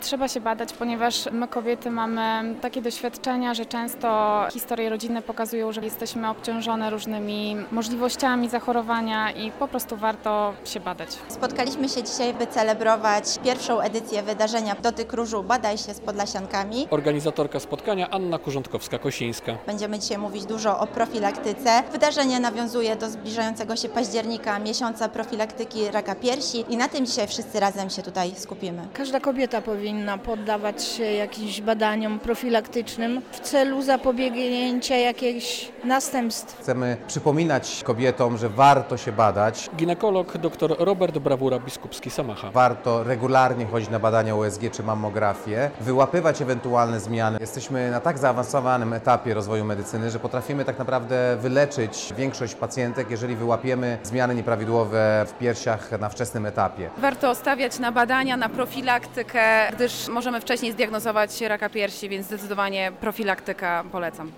Profilaktyka jest ważna, bo wcześnie wykryty nowotwór łatwiej wyleczyć. Przekonywali do tego specjaliści podczas spotkania "Dotyk Różu - badaj się z Podlasiankami." w Łomży.